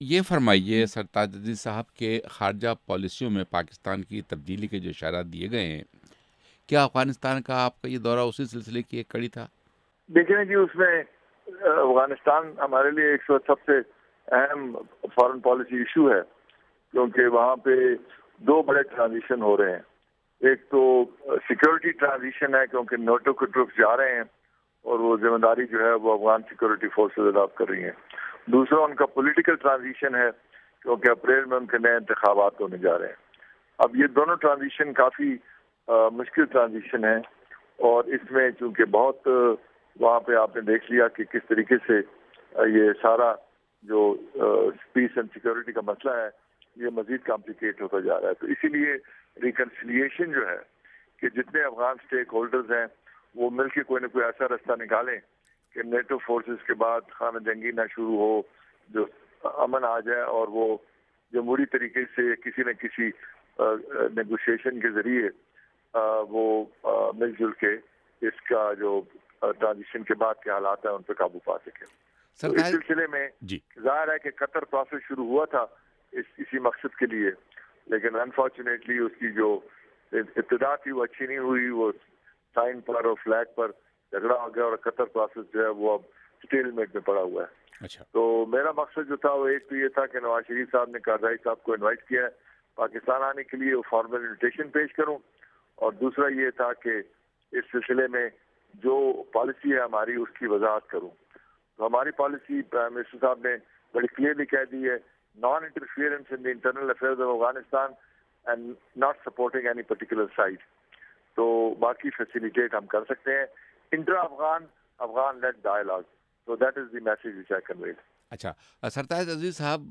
’وائس آف امریکہ‘ سے خصوصی انٹرویو میں، اُنھوں نے کہا کہ اگلے اپریل میں افغانستان کے نئے انتخابات ہونے والے ہیں، جب کہ اسی سال، نیٹو کی افواج کا ملک سے انخلا ہوگا۔
امور خارجہ کے مشیر، سرتاج عزیز کا انٹرویو